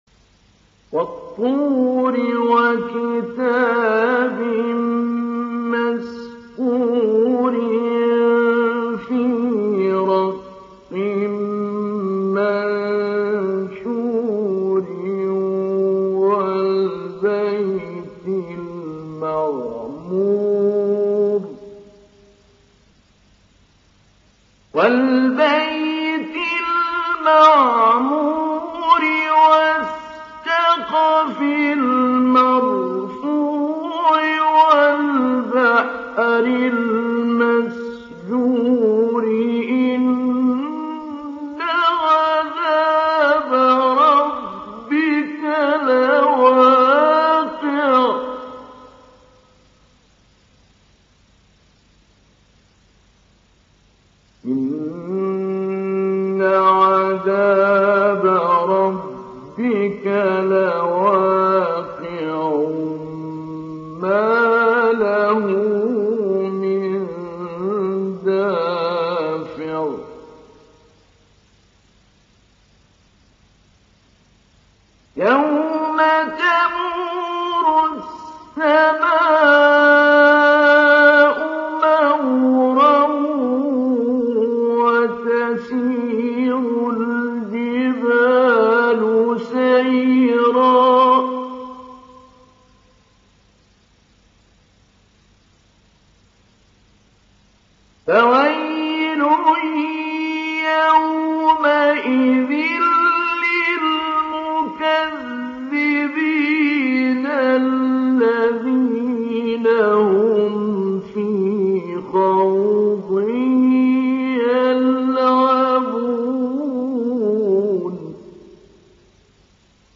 ডাউনলোড সূরা আত-তূর Mahmoud Ali Albanna Mujawwad